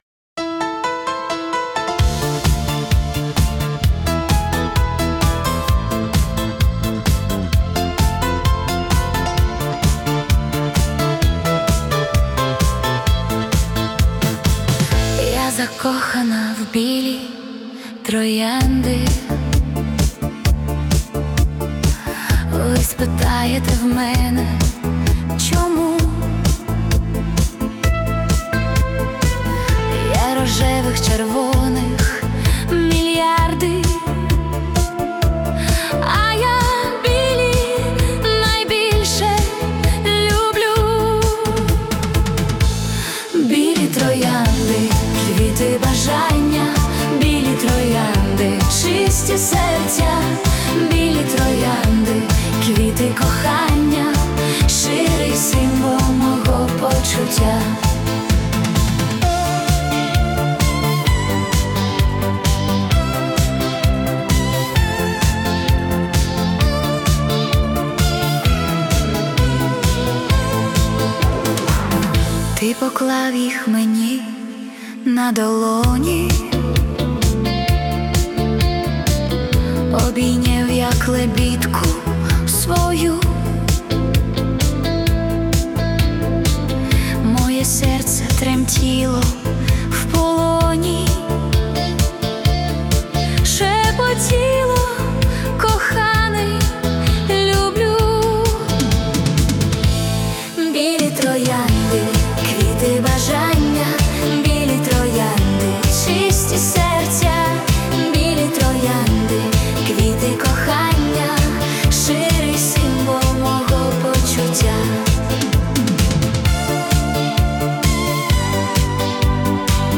🎵 Жанр: Italo Disco / Romantic Duet
Пісня побудована як діалог двох закоханих.
Italo Disco ритм робить це зізнання енергійним і сучасним.